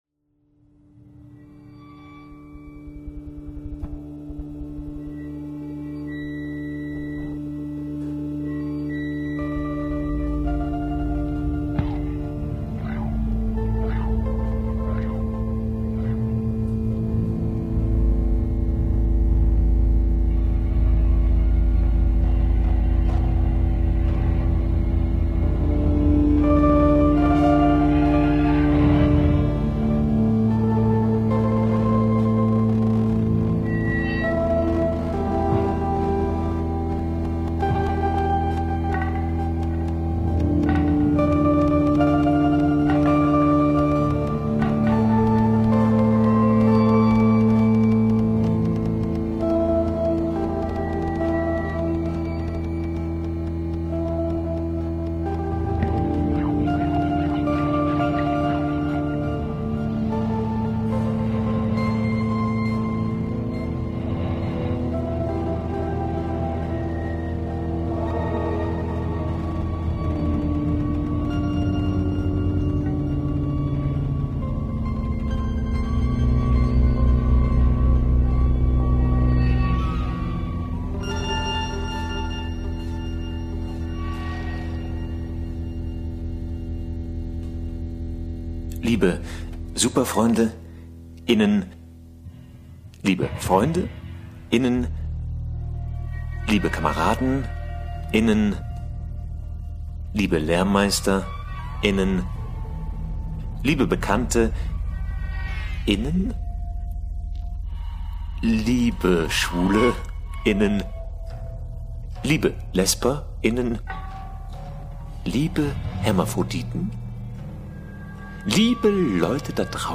Und ab sofort HIER die ganze Radiosendung oder nur das Hörspiel jederzeit hören.